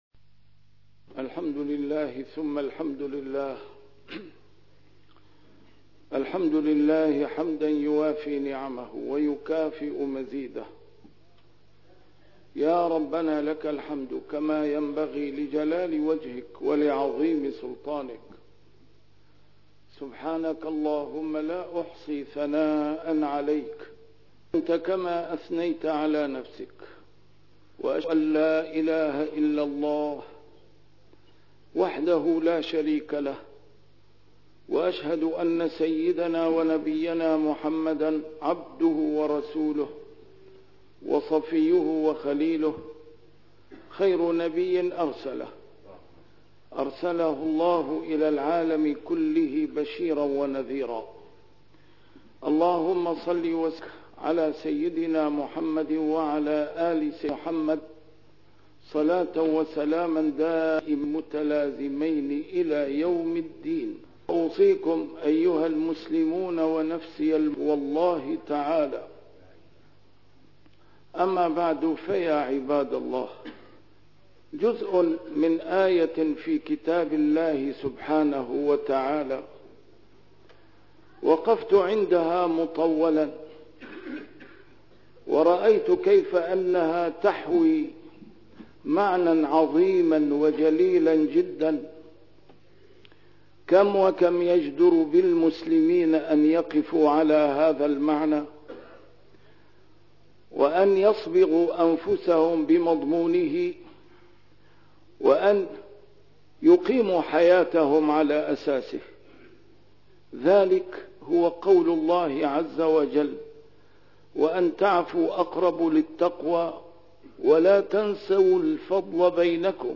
نسيم الشام › A MARTYR SCHOLAR: IMAM MUHAMMAD SAEED RAMADAN AL-BOUTI - الخطب - ولا تنسوا الفضل بينكم - مبدأ عظيم